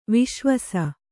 ♪ viśva